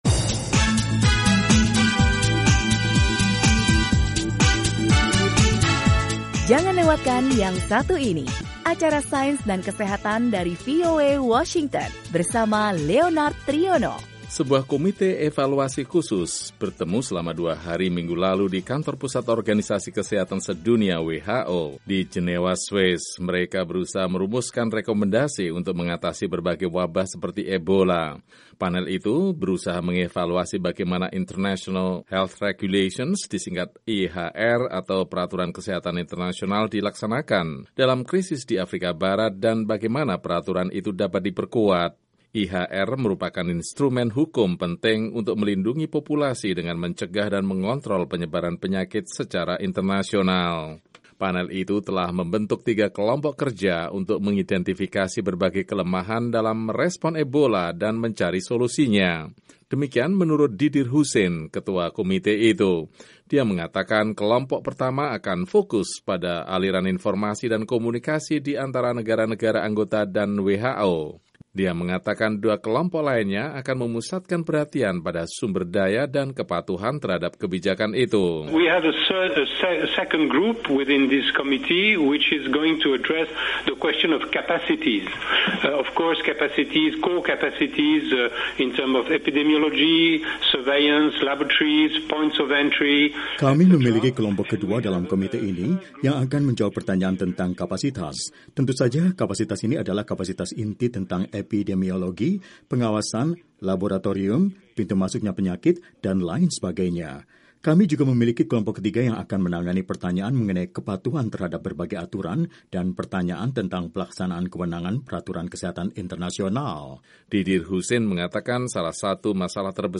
WHO telah memulai proses perumusan pedoman baru bagi tanggapan lebih cepat dan efektif atas wabah Ebola dan berbagai epidemi global lainnya serta penyakit-penyakit yang bermunculan. Ikuti laporan selengkapnya